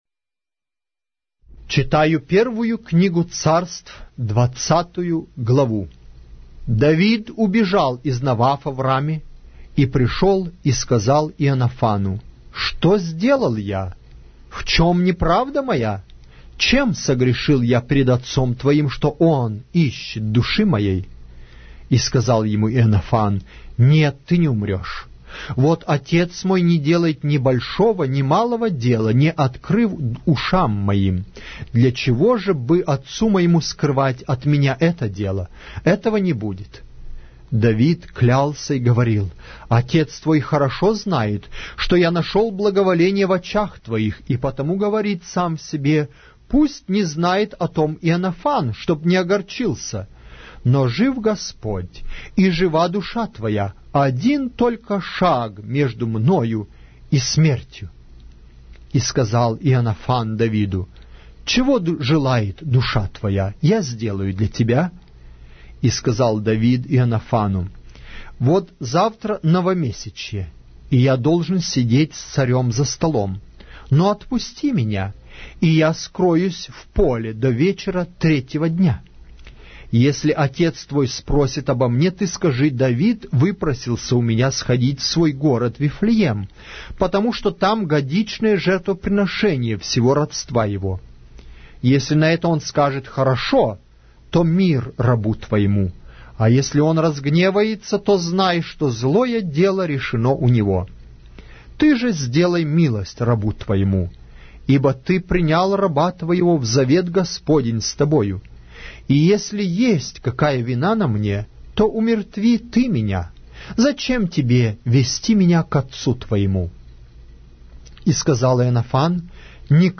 Глава русской Библии с аудио повествования - 1 Samuel, chapter 20 of the Holy Bible in Russian language